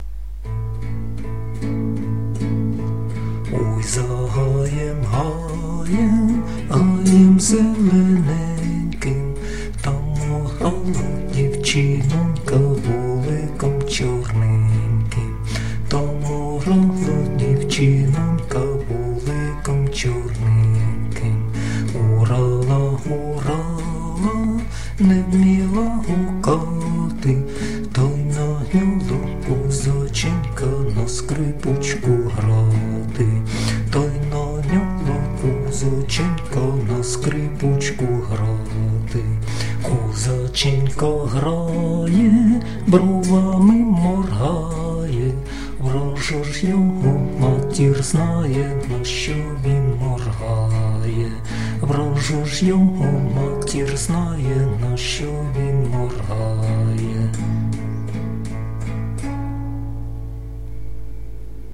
../icons/oymoroz.jpg   Українська народна пiсня